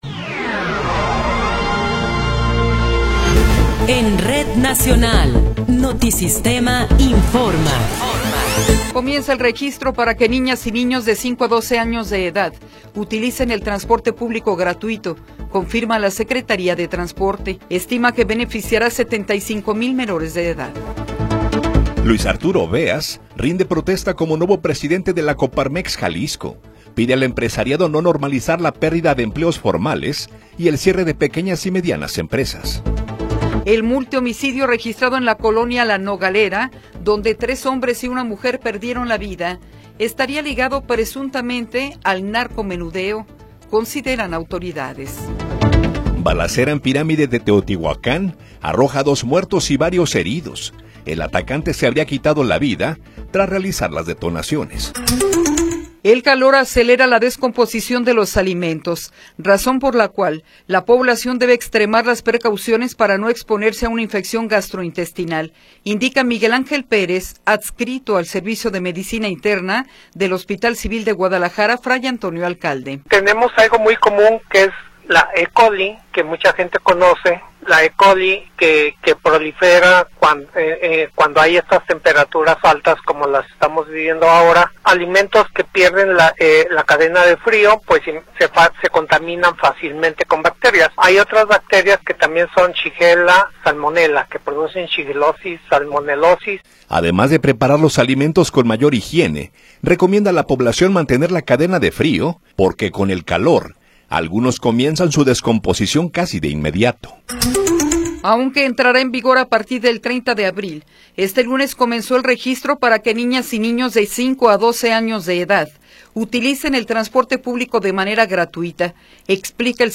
Noticiero 14 hrs. – 20 de Abril de 2026